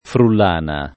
frullana [ frull # na ] s. f.